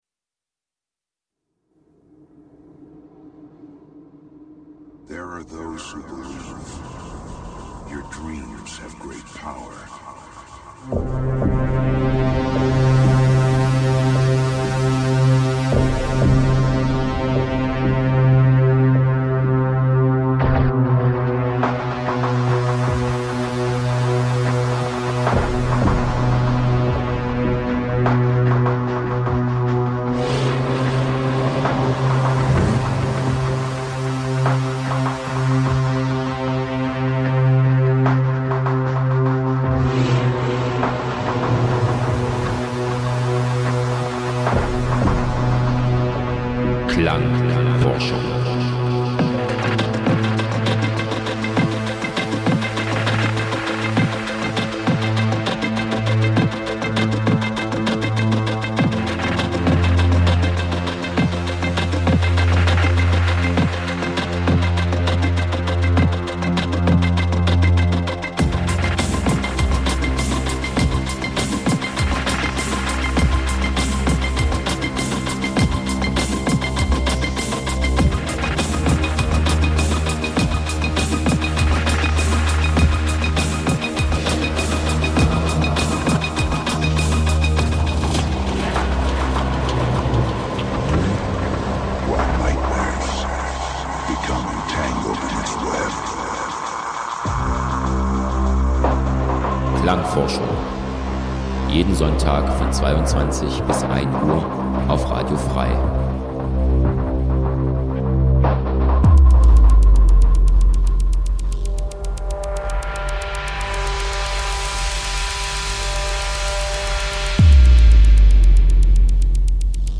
Abstrakte Soundwelten, nie geh�rte Stimmen und musikgewordene Filme f�r Dein Hirnkino, jenseits von Eurodance und Gitarrengeschrammel.
Das Spektrum der musikalischen Bandbreite reicht von EBM , Minimalelektronik, Wave ,Underground 80`s bis hin zu Electro ,Goth und Industriell.
Sendung für elektronische Musik Dein Browser kann kein HTML5-Audio.